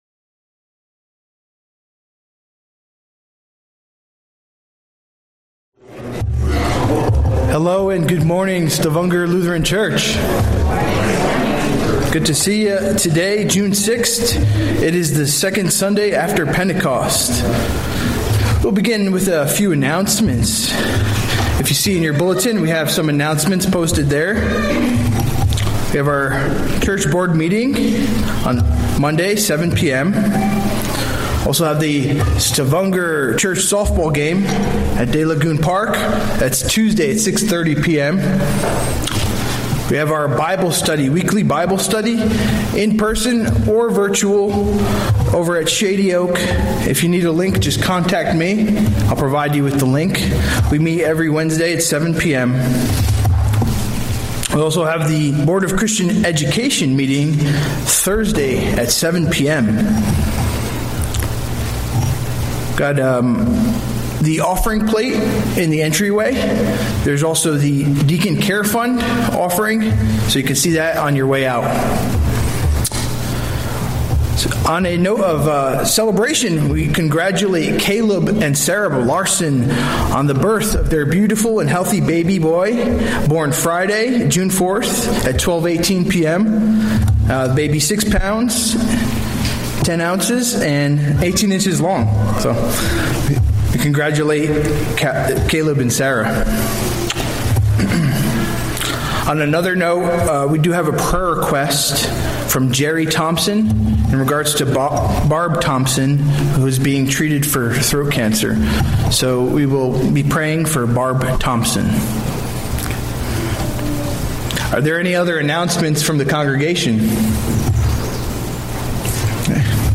From Series: "Sunday Worship"